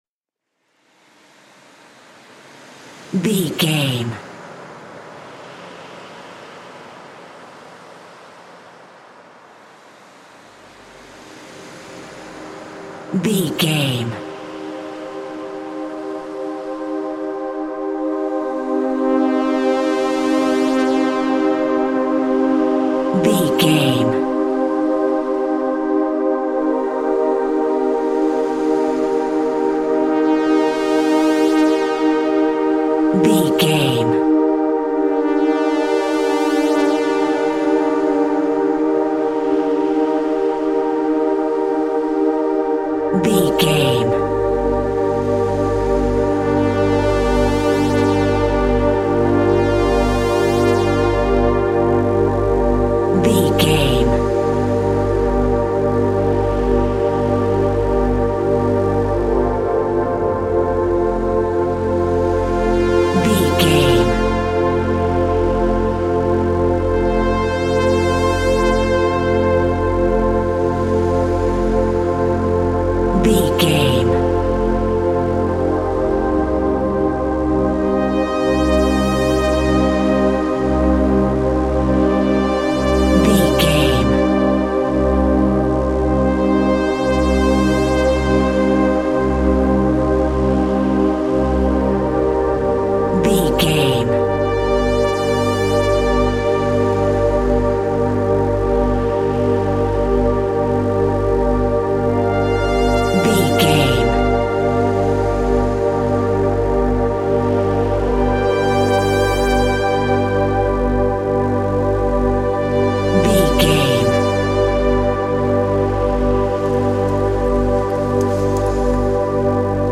Aeolian/Minor
Slow
scary
ominous
dark
eerie
synthesiser
brass
instrumentals